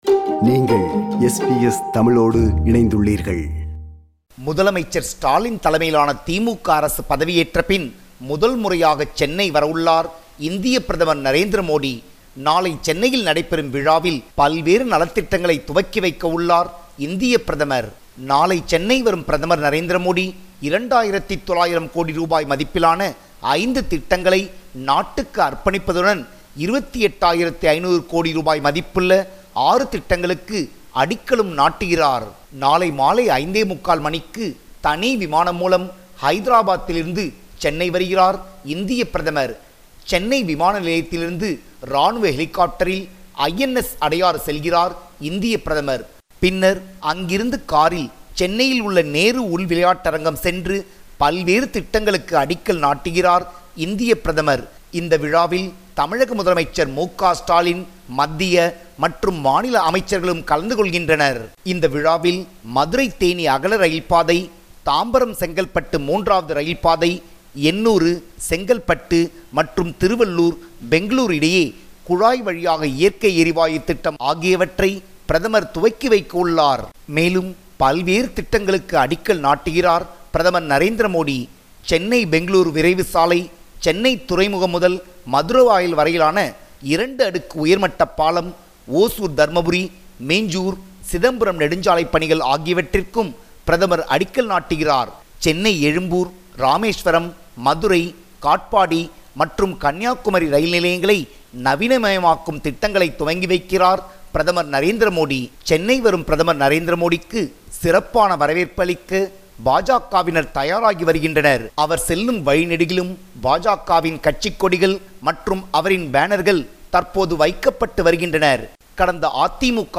compiled a report focusing on major events/news in Tamil Nadu / India.